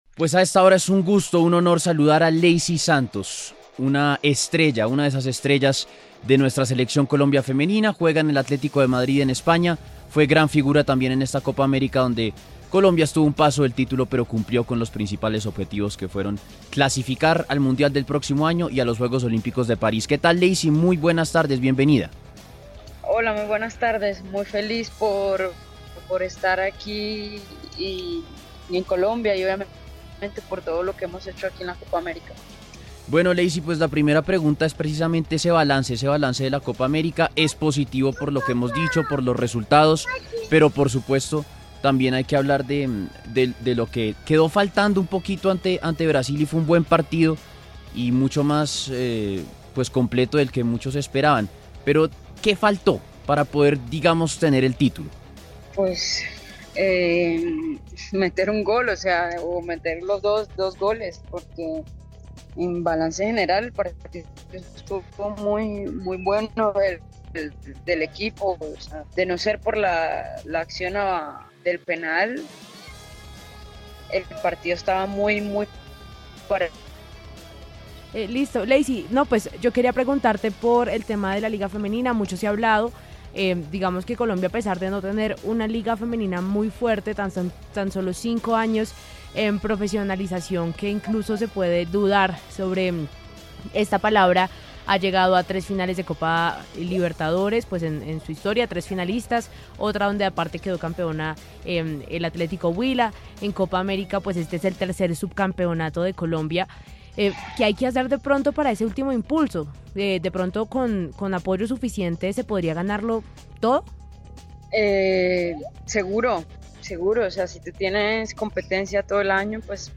A propósito de la excelente participación de la Selección femenina de fútbol de Colombia en la Copa América, la centrocampista ofensiva Leicy Santos conversó para Contrarreloj de W Radio.